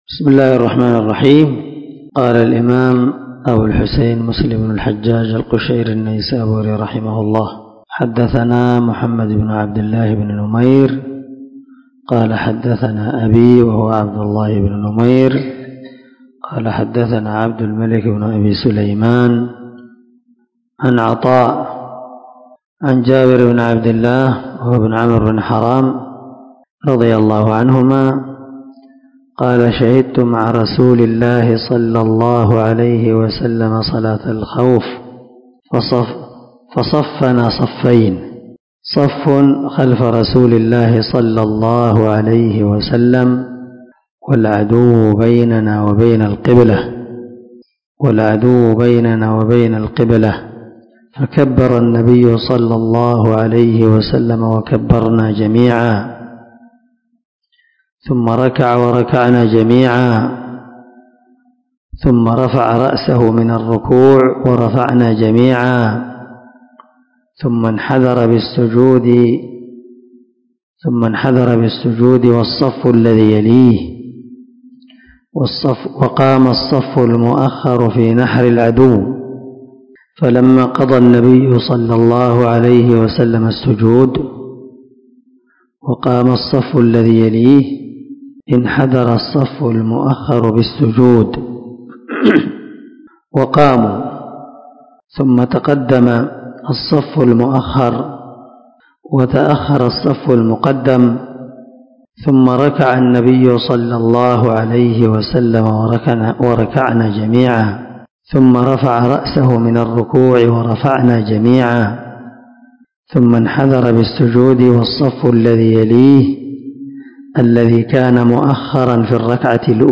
510الدرس 78 من شرح كتاب صلاة المسافر وقصرها حديث رقم ( 840 ) من صحيح مسلم
دار الحديث- المَحاوِلة- الصبيحة.